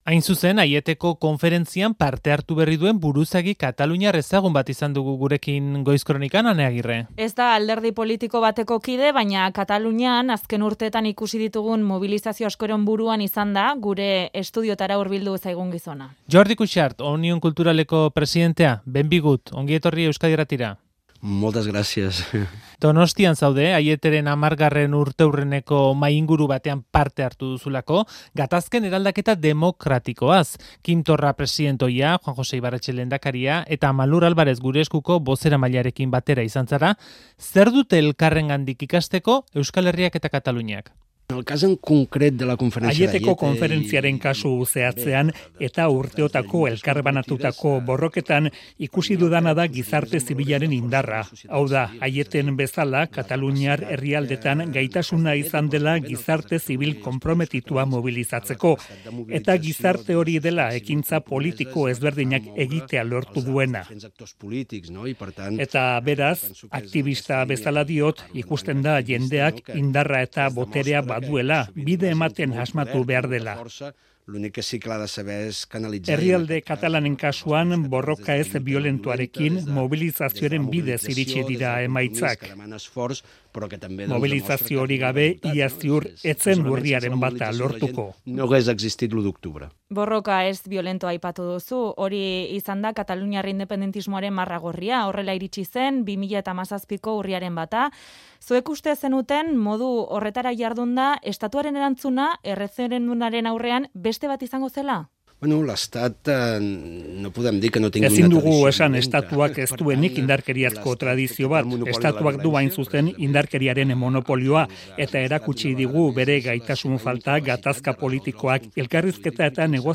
Audioa: Aieteko Konferentziaren 10. urteurrenak ekarri du Donostiara Jordi Cuixart Omnium Culturaleko presidentea. Euskadi Irratiari elkarrizketa bat eskaintzekoa aprobetxatu du bisita.